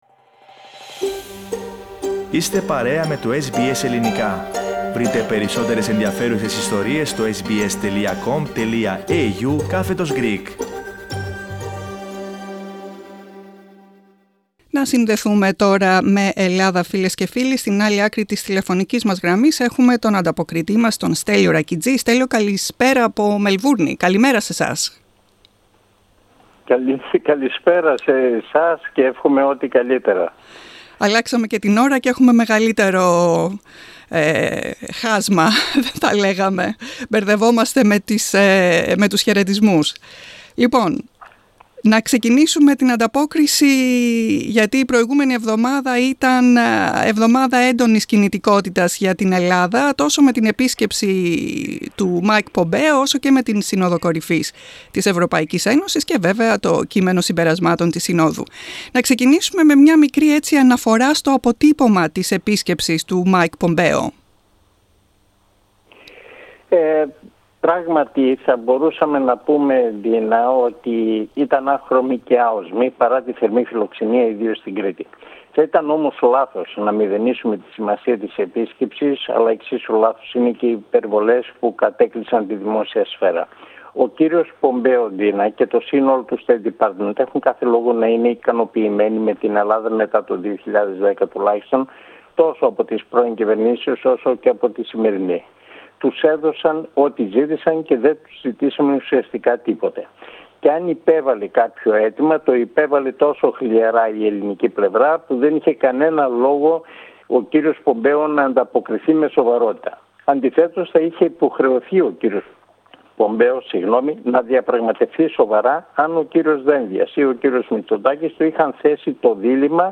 Eλληνοτουρκικά και κορωνοϊός κυριαρχούν στην ανταπόκριση απο Ελλάδα